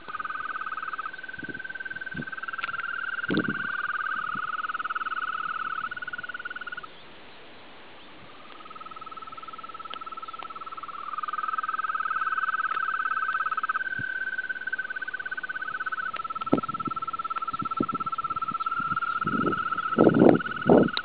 in questi giorni mi trovo in Sicilia nella zona del marsalese.
Saltellava fra l'erba alta ai margini di uno stagno mentre fra i canneti si sentivano dei richiami amorosi che sentivo per la prima volta e che penso siano dell'anfibio in questione.
e con il canto amoroso dello stesso concludo...
Sembra in tutto e per tutto il canto di Rospi smeraldini...
belle foto e ambiente molto interessante Per il resto confermo: hai registrato un Bufo siculus o viridis s.l che dir si voglia